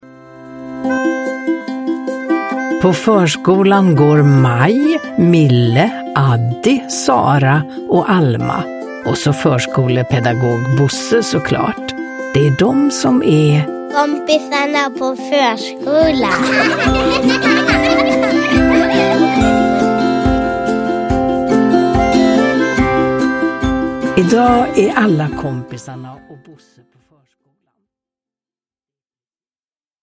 Jul på förskolan – Ljudbok
Uppläsare: Ulla Skoog